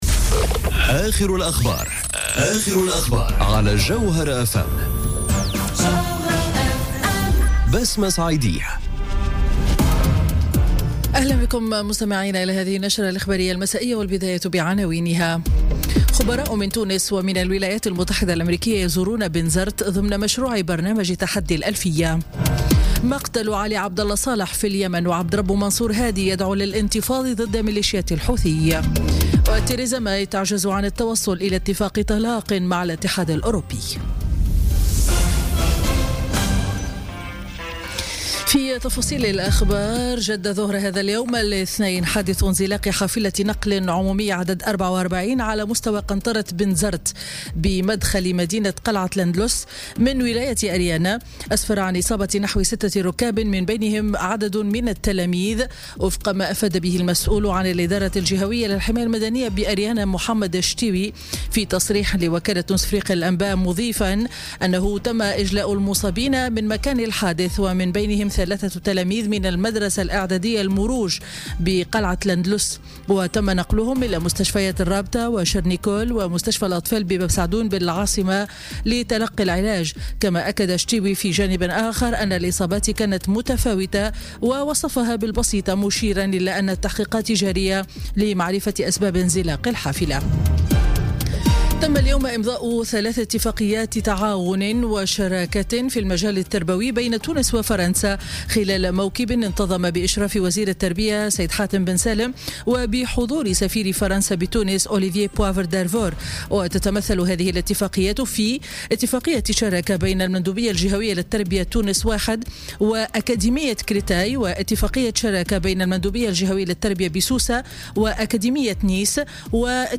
نشرة أخبار السابعة مساء ليوم الاثنين 04 ديسمبر 2017